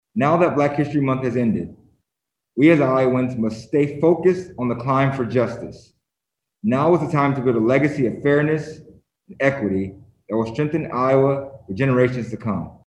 Smith says after the police reform package that passed unanimously last June, the G-O-P controlled legislature appears to be taking Iowa backwards.
Four other black members of the Iowa House joined Smith for an online news conference this (Thursday) morning.